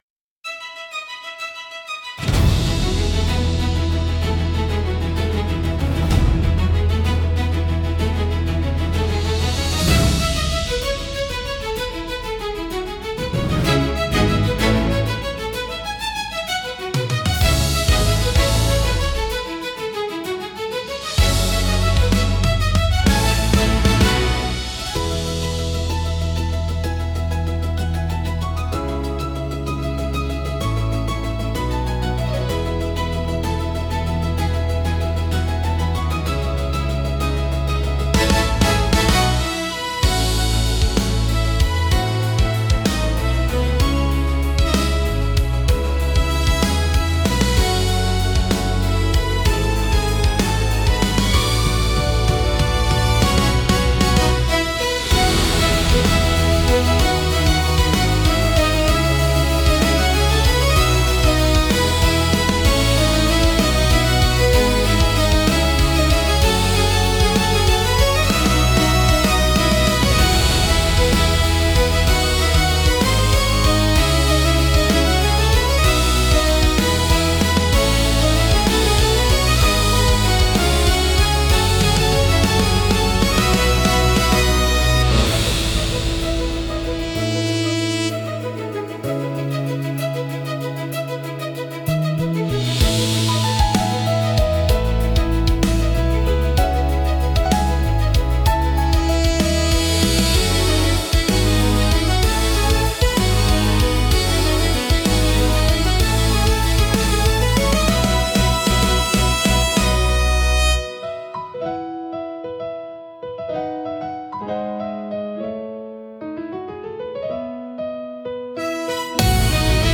優雅なストリングスと力強いブラスが重なり合い、広がるコーラスが新たな始まりの高揚感と希望をドラマチックに表現します。